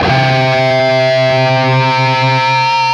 LEAD C 2 CUT.wav